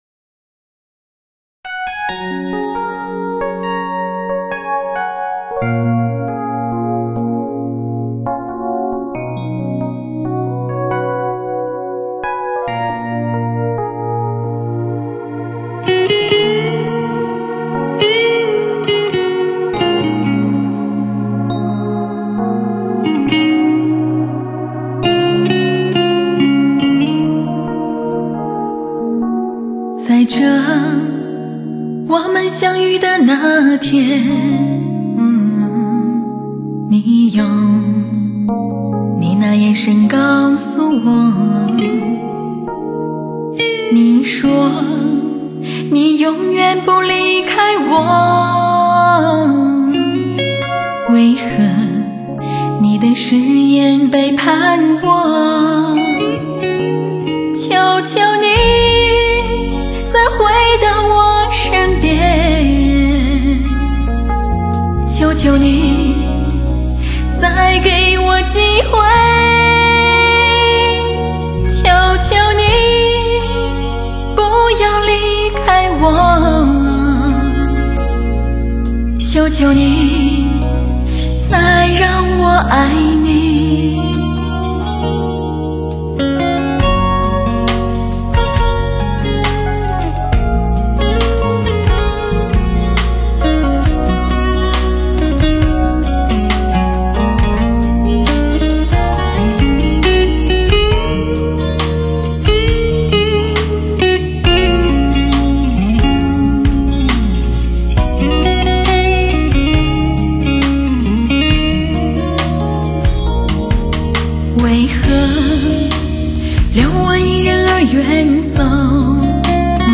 瑞典制造母带 顶级HI-FI人声 声声如幻